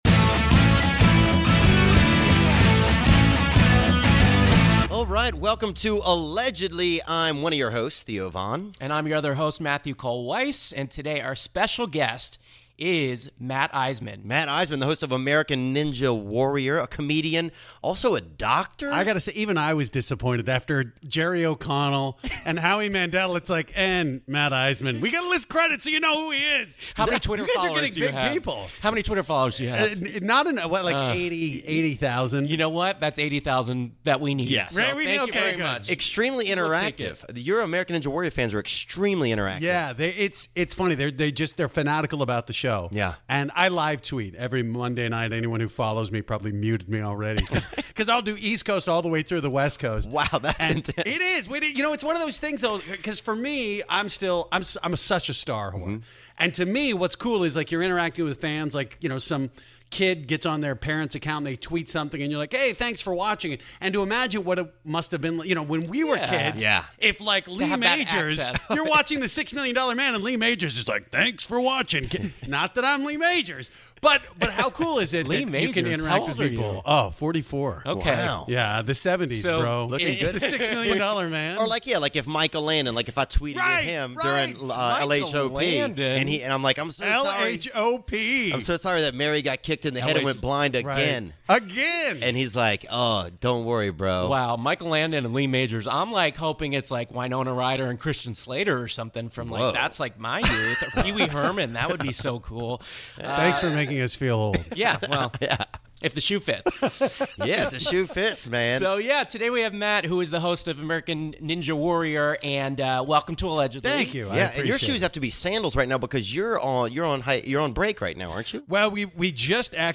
The Host of American Ninja Warrior MATT ISEMAN (who gave up his job as a DOCTOR to become a comedian) comes down from MT MIDORIYAMA to be our GUEST on this episode of Allegedly. Tune in to find out who is guilty of racial profiling at Snoop Dog’s birthday party, who made late night love to a cat lady, IF the American Ninja Warrior tests its ninjas for performance enhancing drugs AND to hear if Taylor Lautner will accept the challenge to run the American Ninja Warrior course.